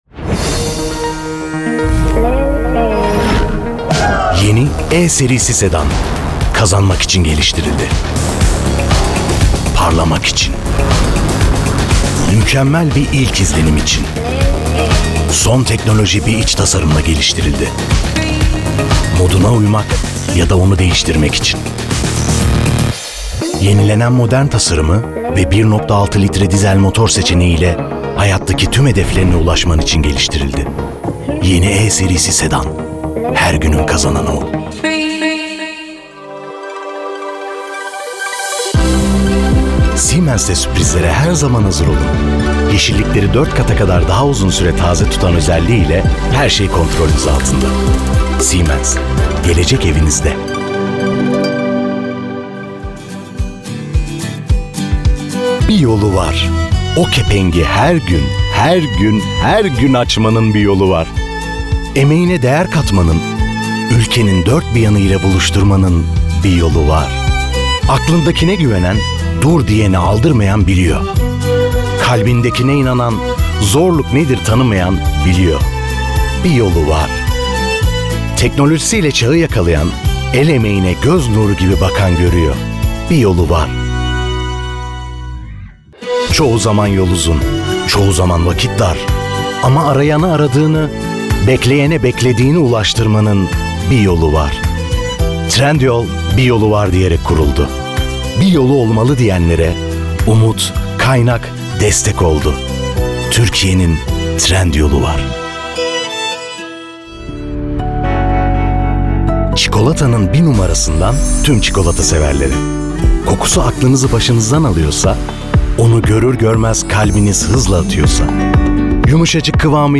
DEMO SESLERİ
KATEGORİ Erkek
Belgesel, Canlı, Spiker, Fragman, Güvenilir, Havalı, Seksi, Karizmatik, Vokal, Sıcakkanlı, Tok / Kalın, Dış Ses, Olgun,